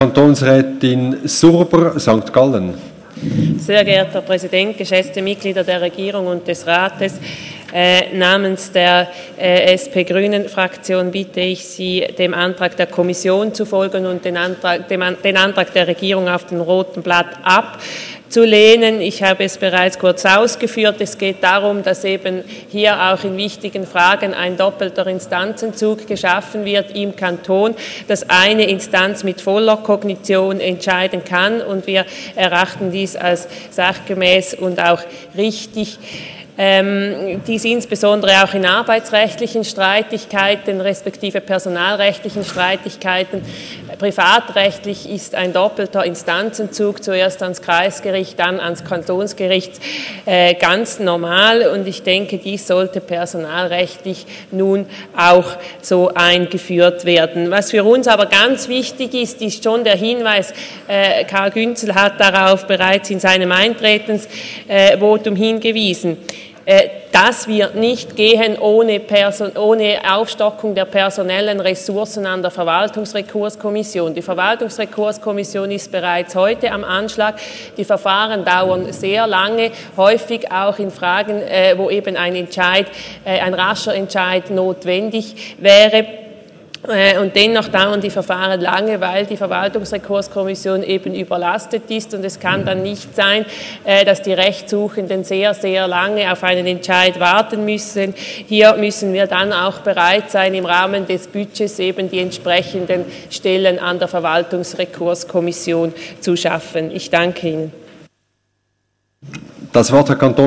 20.9.2016Wortmeldung
Session des Kantonsrates vom 19. und 20. September 2016